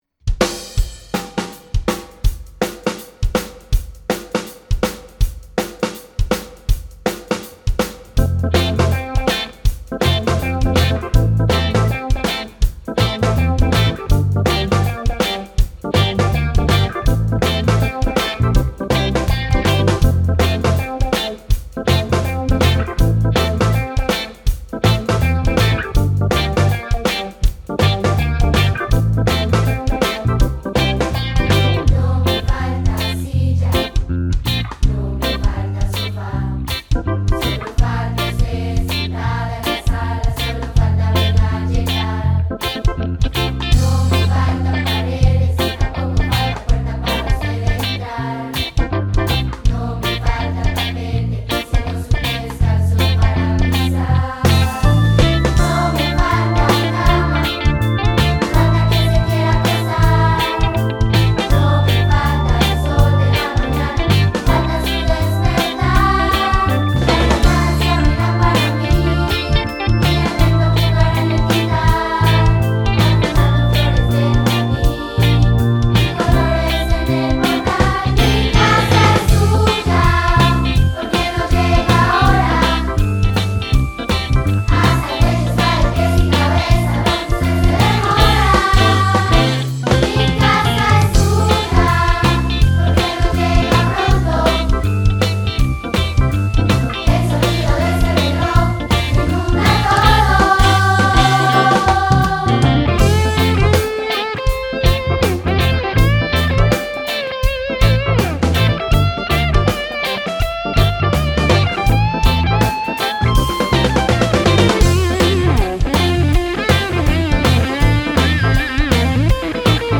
(Tonalidad F Mayor)
Grabación en ensayo